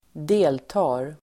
Uttal: [²d'e:lta:r]